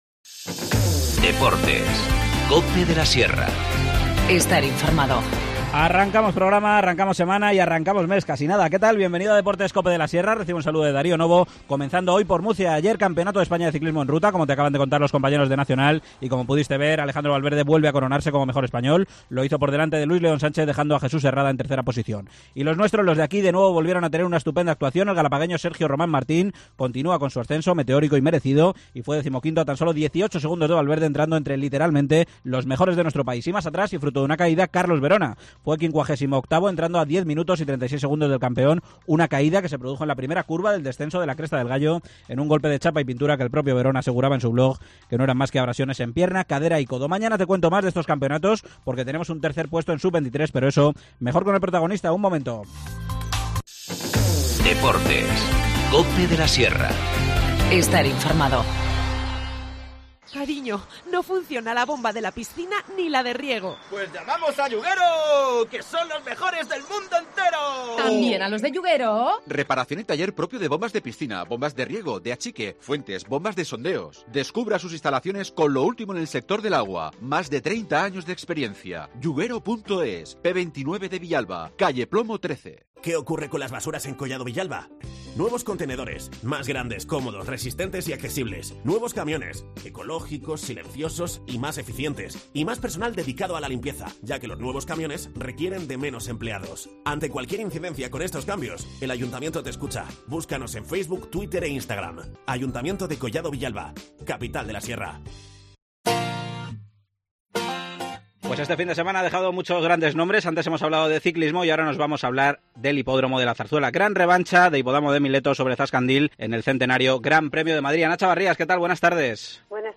Deportes local